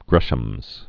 (grĕshəmz)